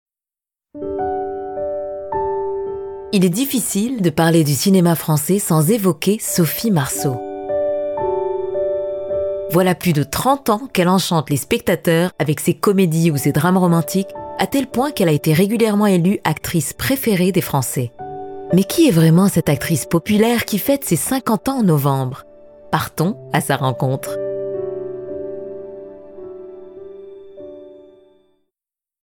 Institutionnel DM